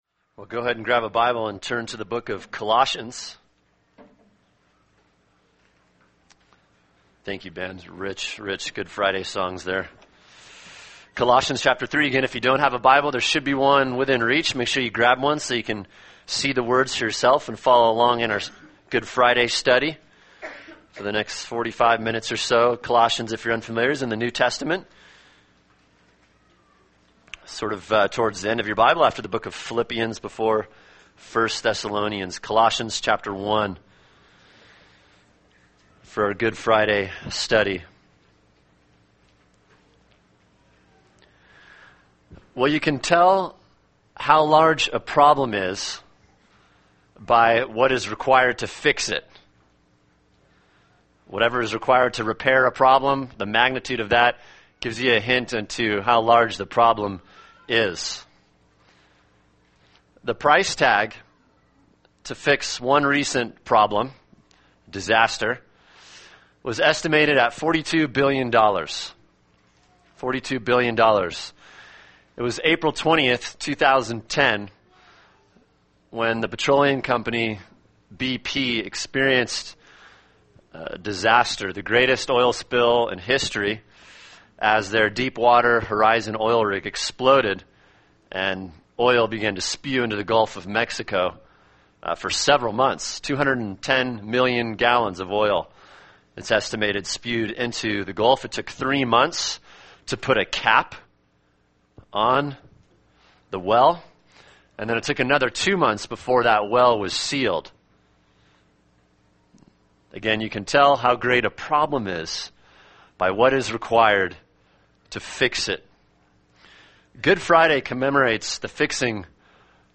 [sermon] Reconciled Through Atonement (Good Friday) | Cornerstone Church - Jackson Hole